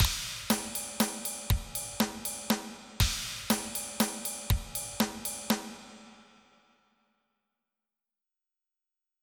• Качество: 321, Stereo
громкие
без слов
ударные
барабаны